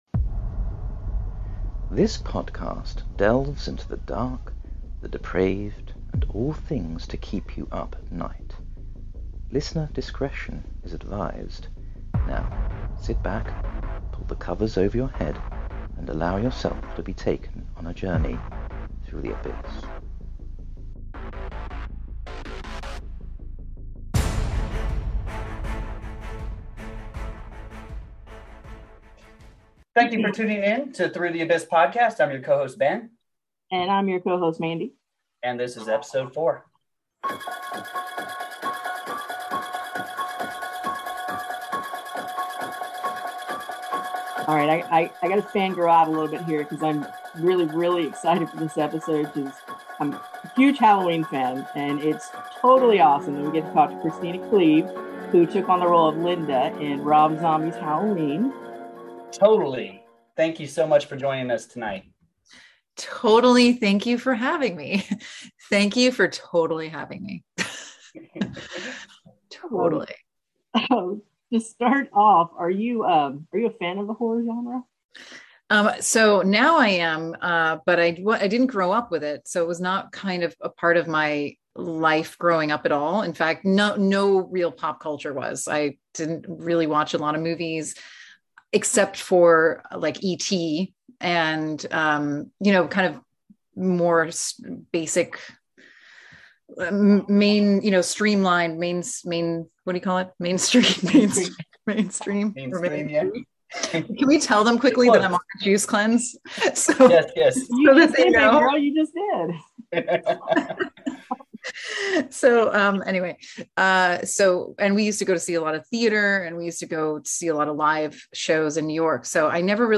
TC: EP 4 - Halloween's Kristina Klebe interview